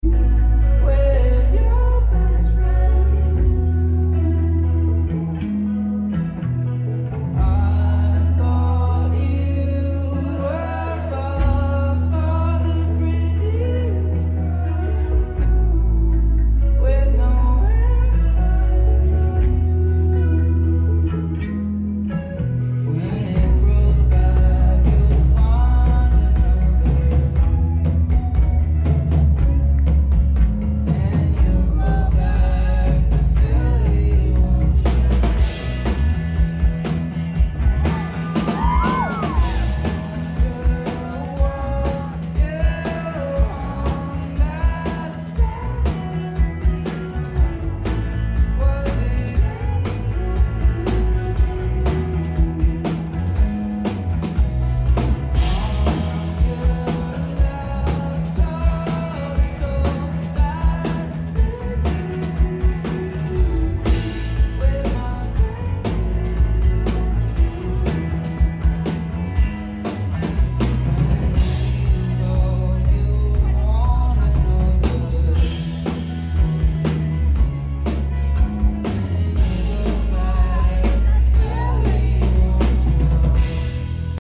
beautiful vocals